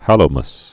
(hălō-məs, -măs)